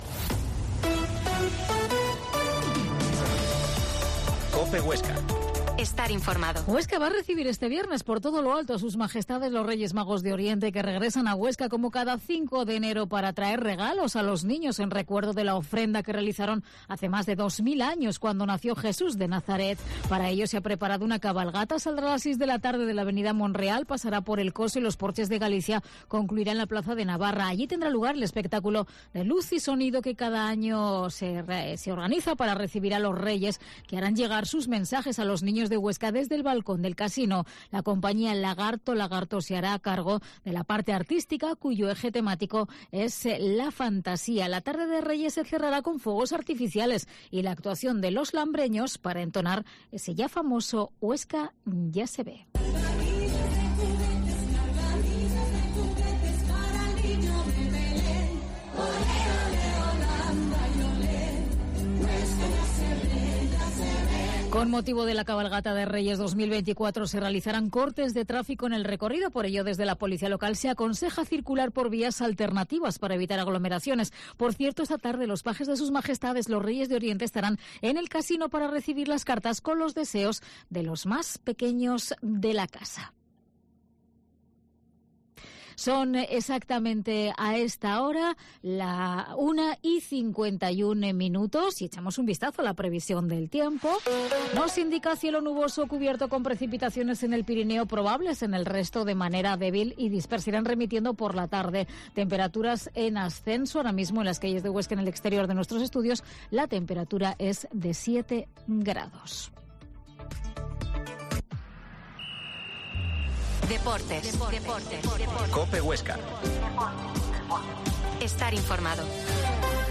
La Mañana en COPE Huesca - Informativo local
Entrevista a la jefa provincial de Tráfico en Huesca, Margarita Padial